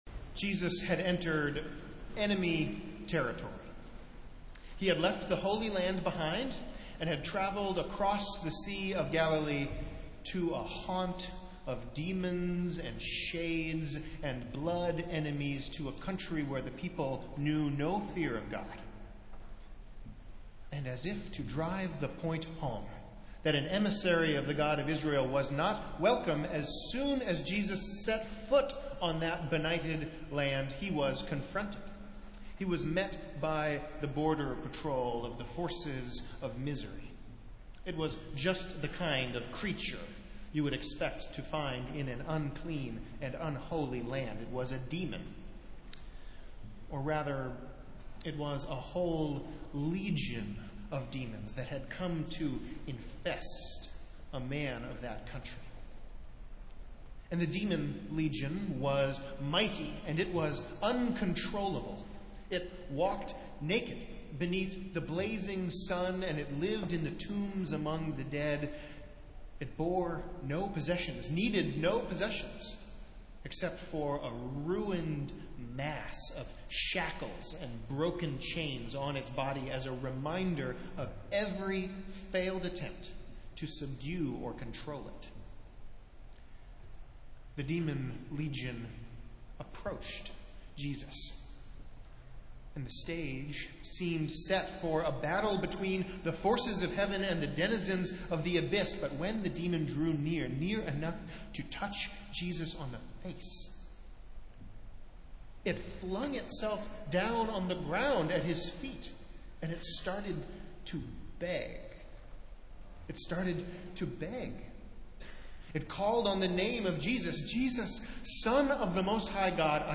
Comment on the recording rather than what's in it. Festival Worship - Fifth Sunday after Pentecost